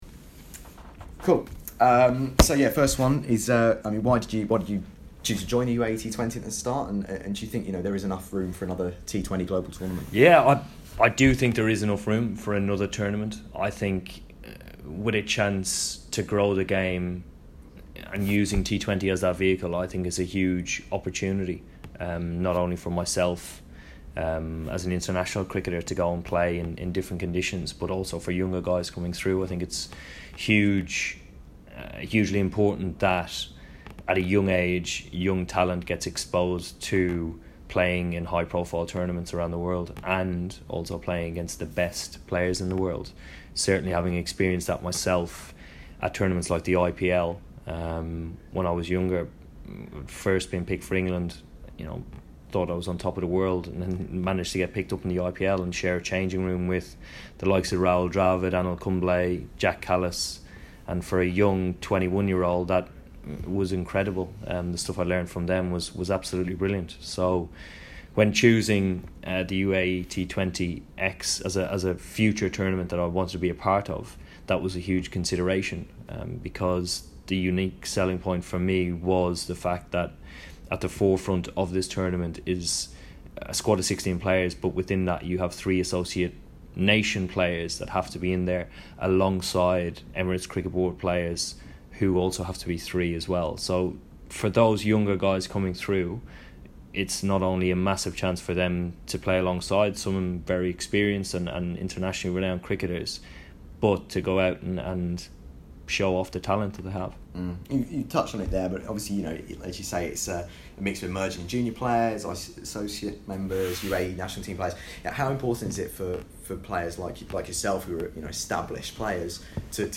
Eoin Morgan UAE T20 Interview